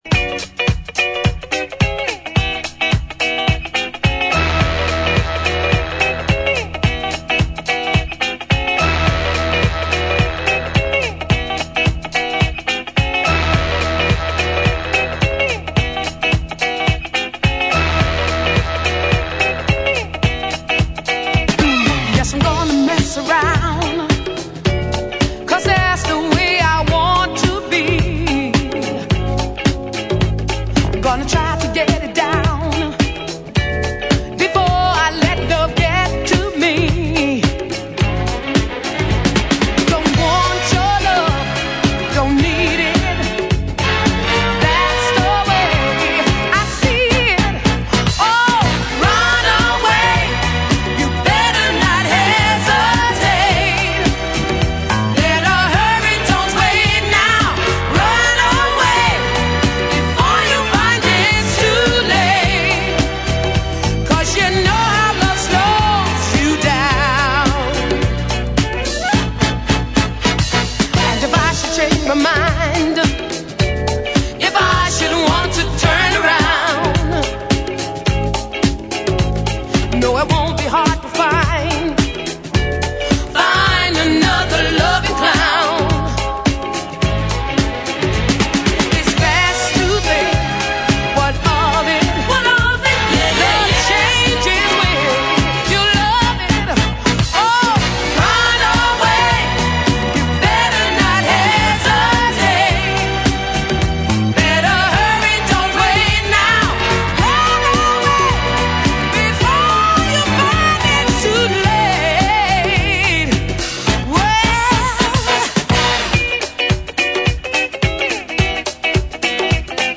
ジャンル(スタイル) DISCO / SOUL / FUNK / RE-EDIT